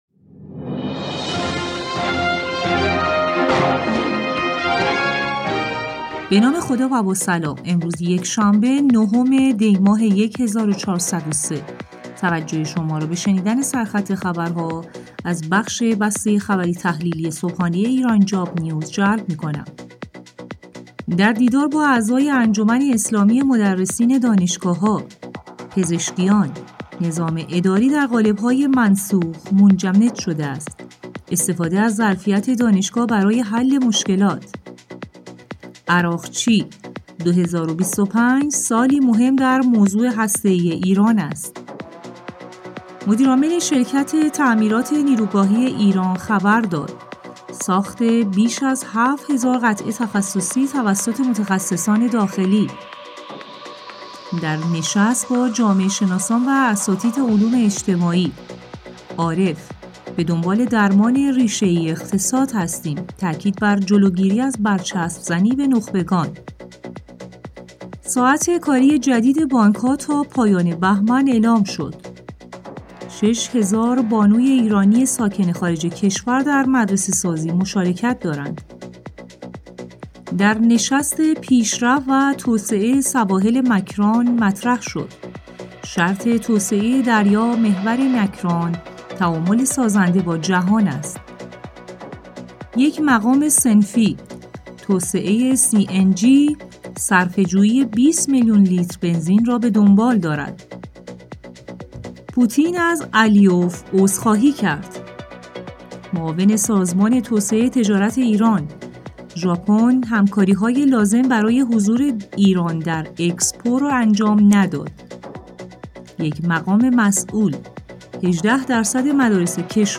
بسته خبری‌تحلیلی صبحانه ایران‌جاب‌نیوز؛ یکشنبه، ۹ دی ماه ۱۴۰۳